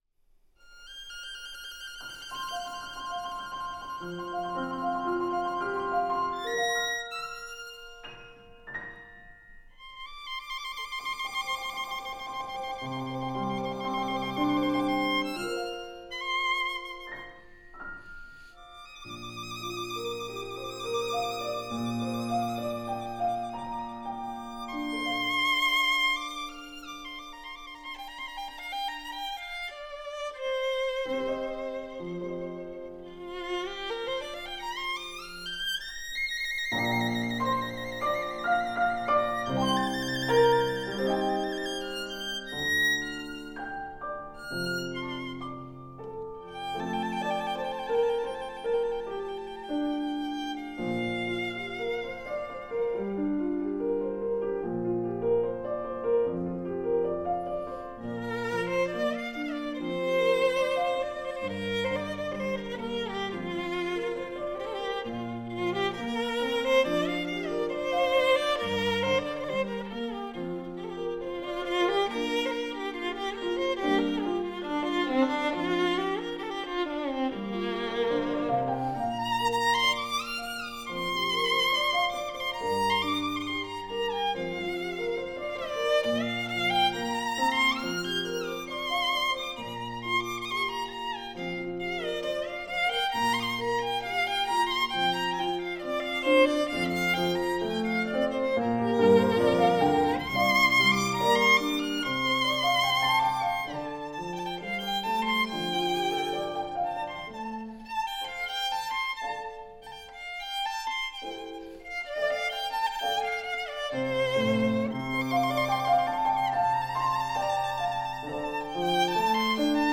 钢琴
(1995年12月录于北京中国解放军军乐团录音棚)
引子清脆的钢琴声与明亮的小提琴声，
主题旋律尤能发挥小提琴的甜美音色，
只是小提琴位置偏右令我们有点不习惯。